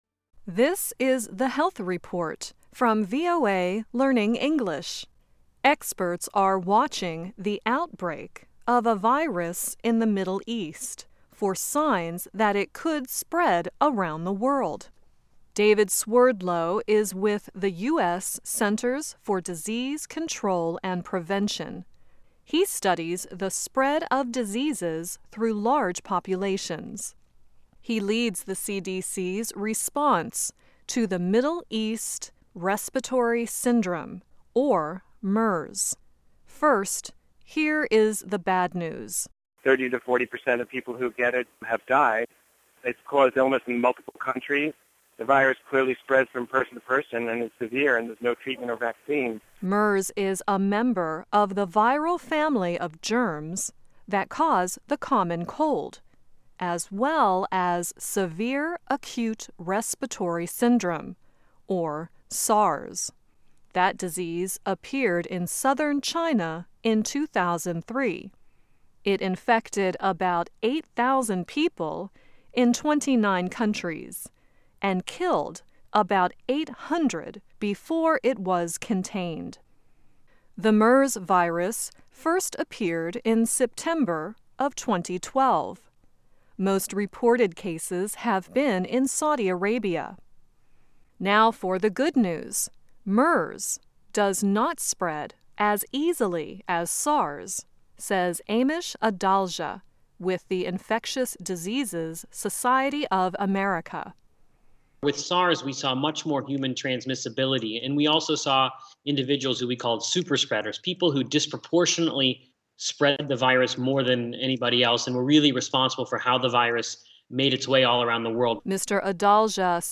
Learn English as you read and listen to a weekly show about developments in science, technology and medicine. Our stories are written at the intermediate and upper-beginner level and are read one-third slower than regular VOA English.